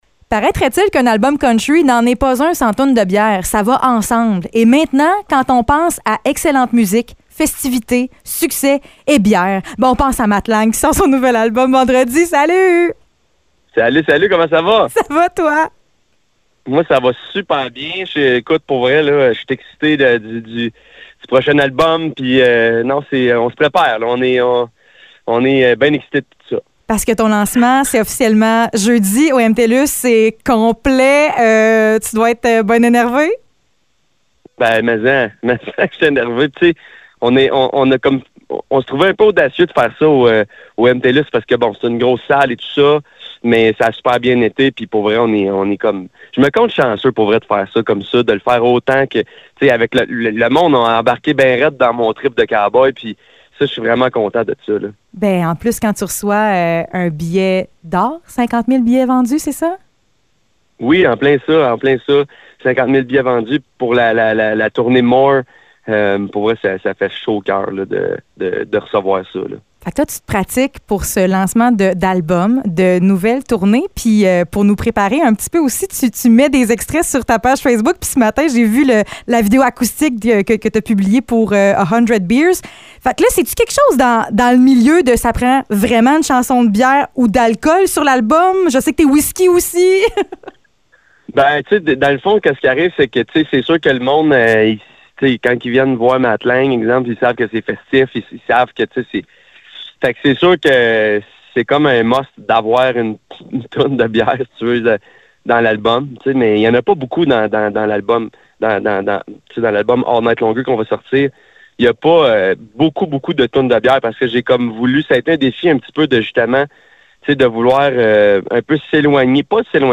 Entrevue avec Matt Lang